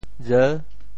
饵（餌） 部首拼音 部首 飠 总笔划 14 部外笔划 6 普通话 ěr 潮州发音 潮州 ri7 文 re6 文 潮阳 ri7 ru6 澄海 ri7 re6 揭阳 ri7 re6 饶平 ri7 re6 汕头 ri7 re6 中文解释 饵 <名> (形声。